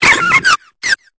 Cri de Rozbouton dans Pokémon Épée et Bouclier.